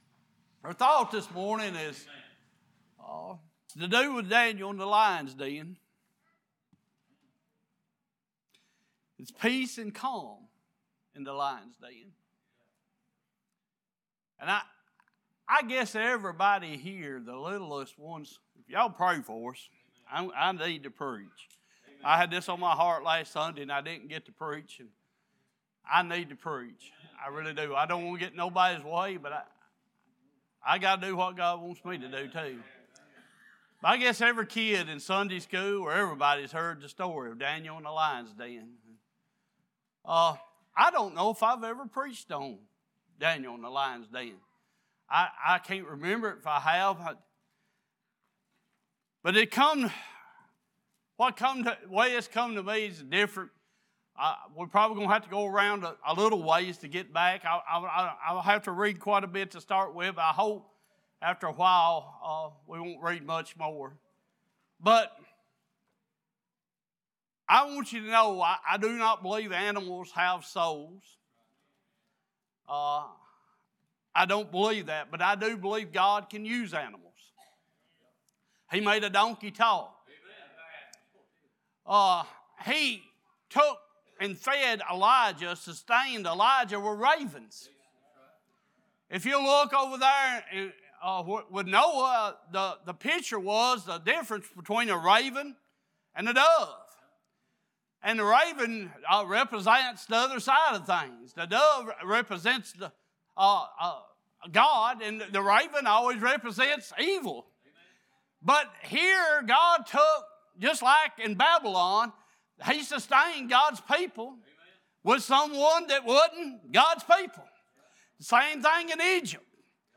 Sunday Morning Passage